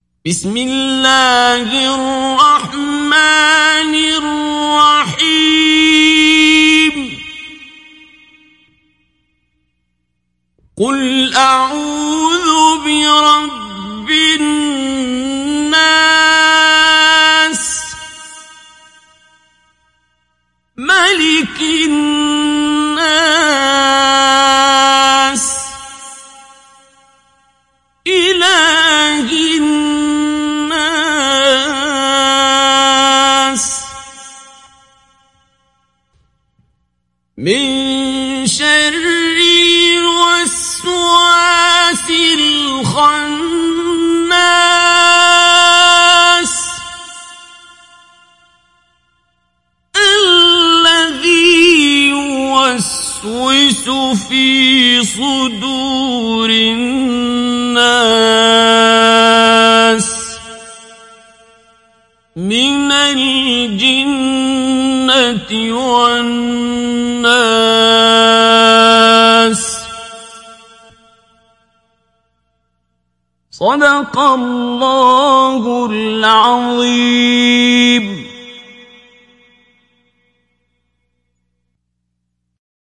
Mujawwad